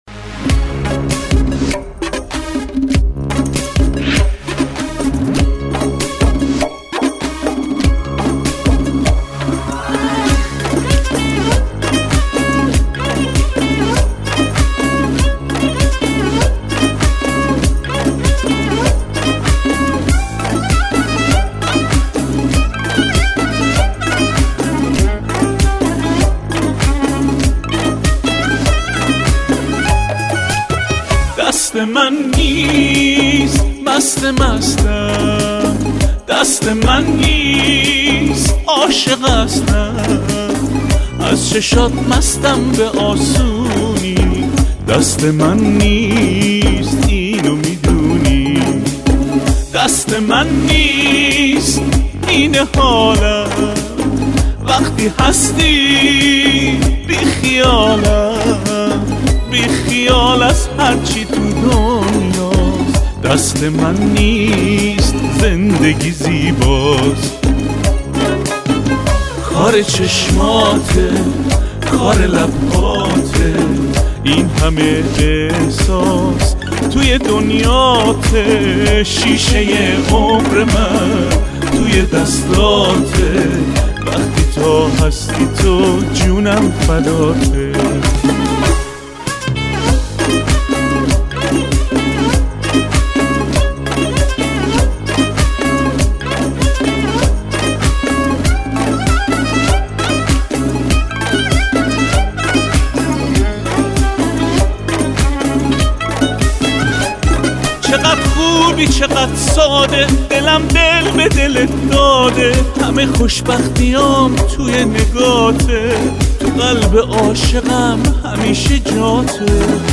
آهنگ احساسی آهنگ قدیمی